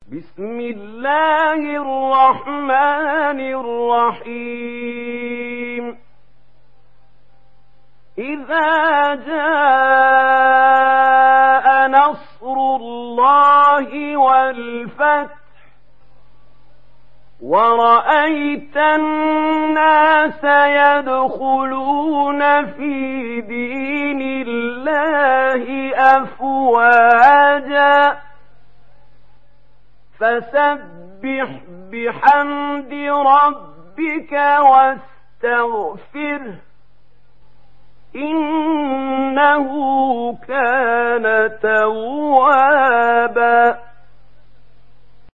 دانلود سوره النصر mp3 محمود خليل الحصري (روایت ورش)